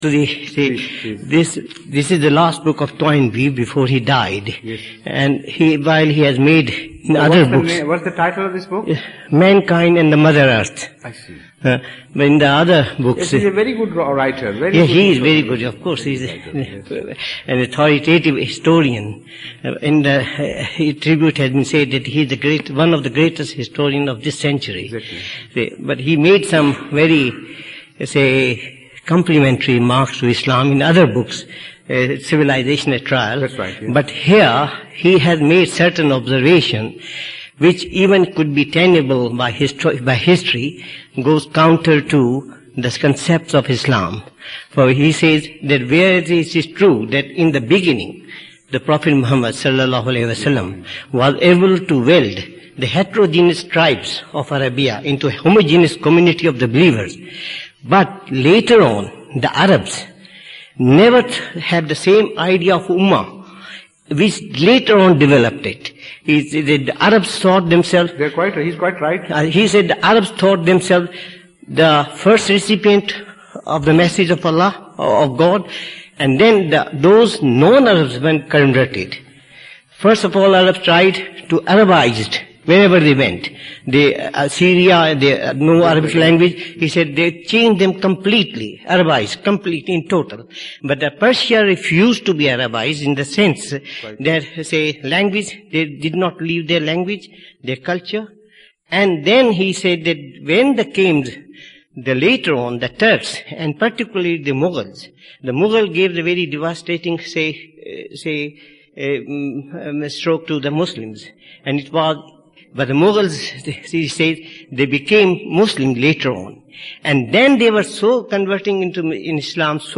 The London Mosque